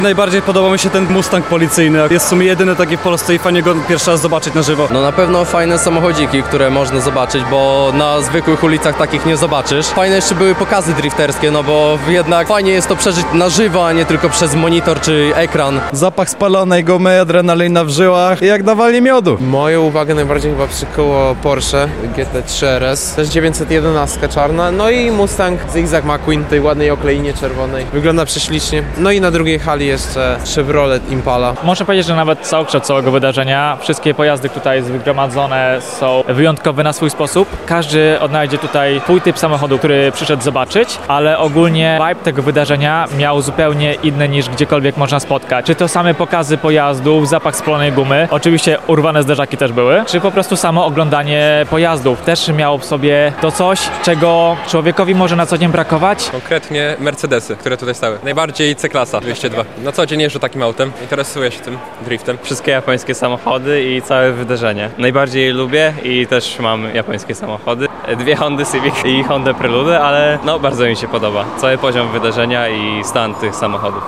Za nami Moto Session 2026!
Zapytaliśmy uczestników, co najbardziej przyciągnęło ich uwagę.